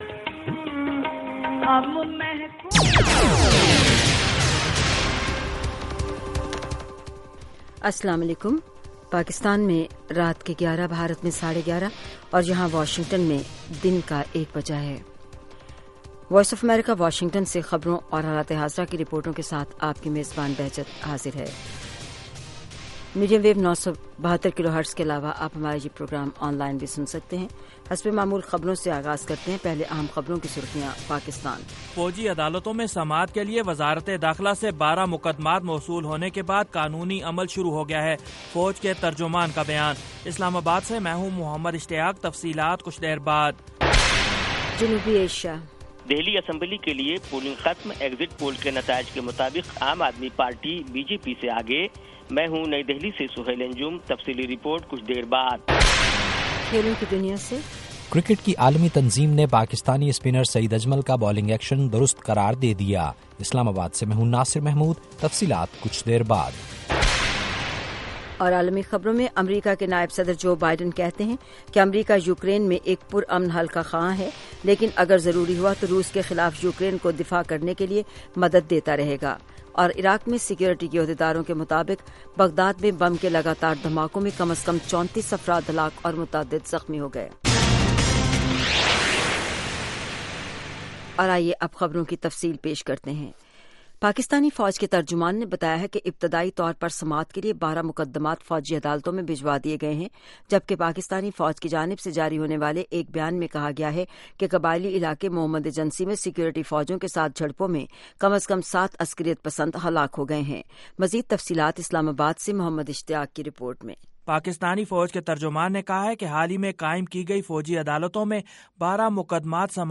11:00PM اردو نیوز شو